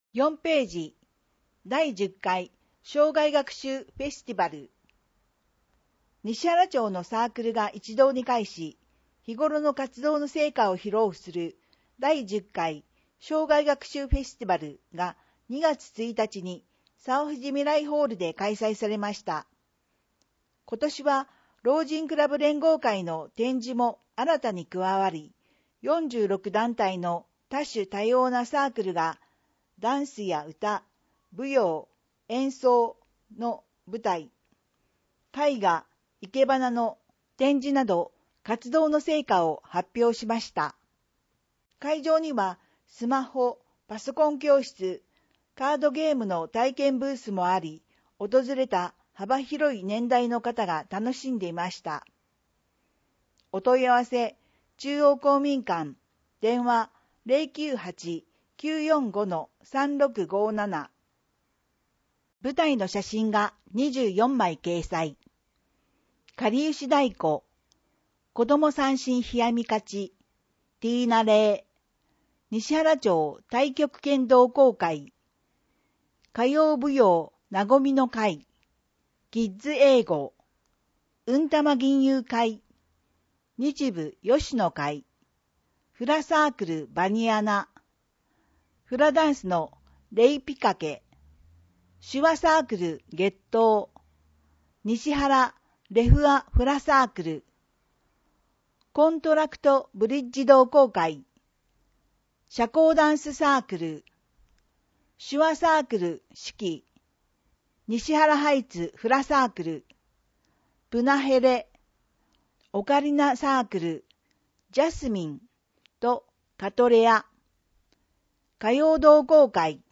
声の広報にしはらは、広報にしはらの情報を音声でお届けしています。
音訳ボランティアサークル「声の広報かけはし」が録音しています。